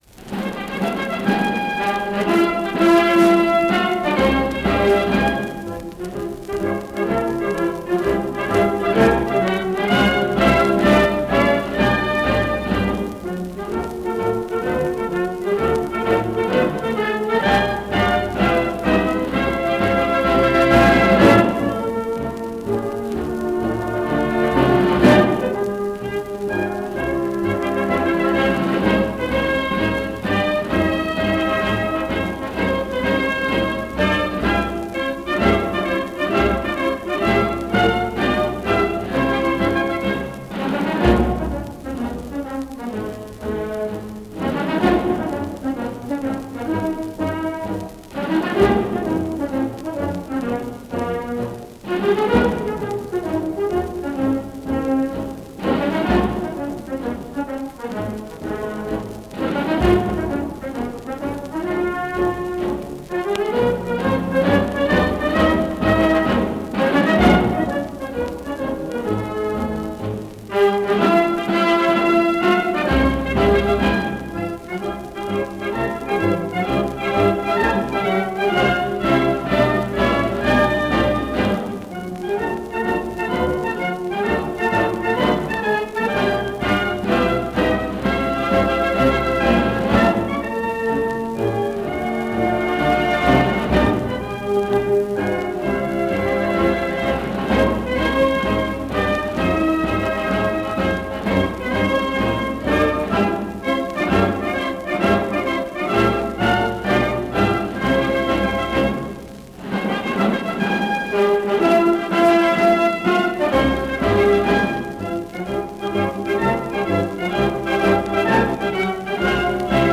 Pasodoble con cornetas y tambores.